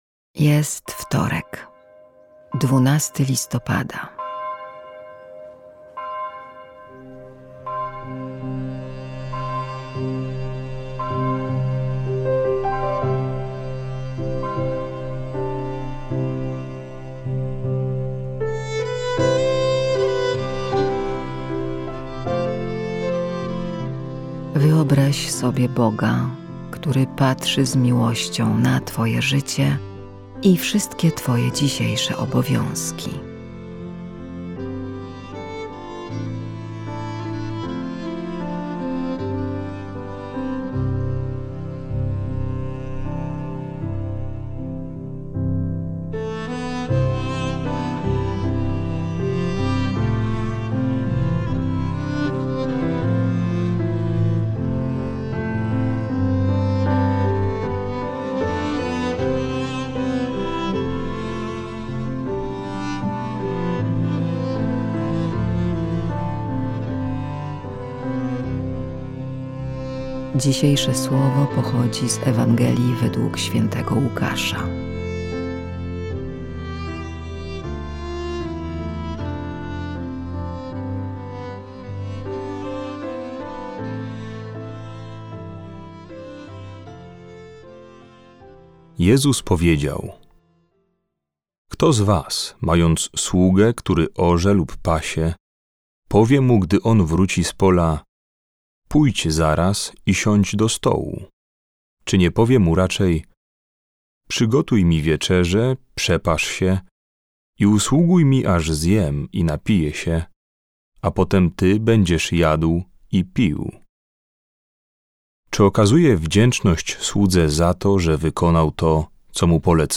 Polscy Jezuici, idąc za przykładem swoich braci z Wielkiej Brytanii, zaproponowali serie około dziesięciominutowych rozważań łączących muzykę i wersety z Biblii, pomagające odkrywać w życiu Bożą obecność i pogłębić relacje z Bogiem.